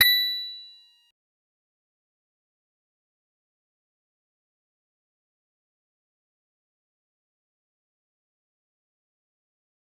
G_Musicbox-B7-mf.wav